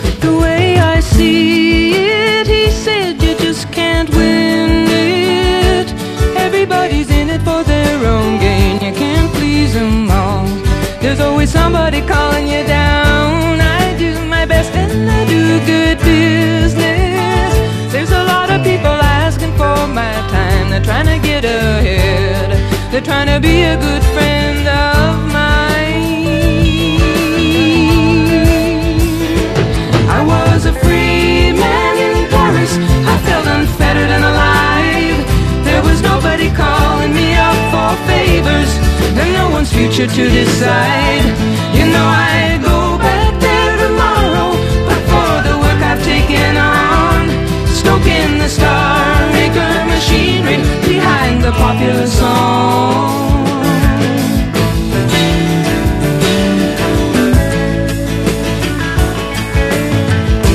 ROCK / 90''S～ / INDIE POP / SURF / GARAGE PUNK / GUITAR POP
ギターポップ/インディー系バンドによるサーフ/ホットロッド・オムニバス10インチ！